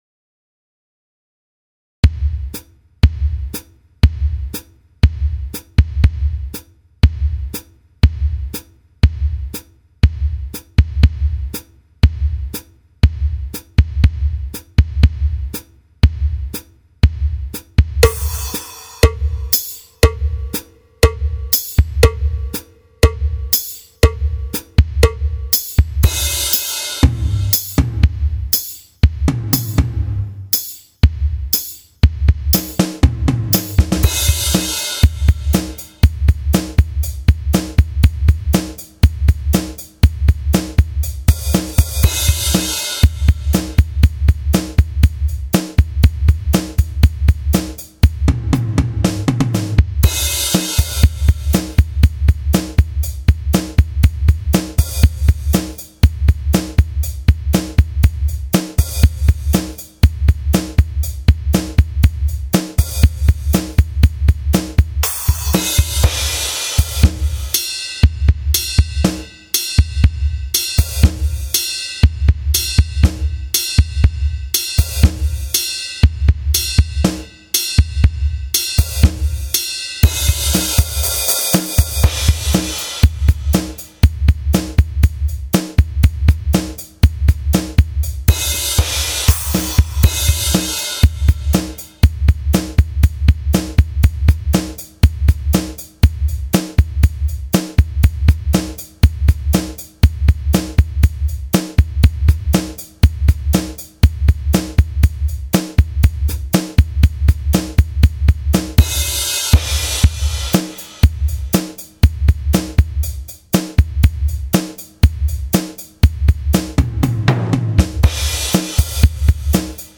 Drum Grooves
06 Rock 120.mp3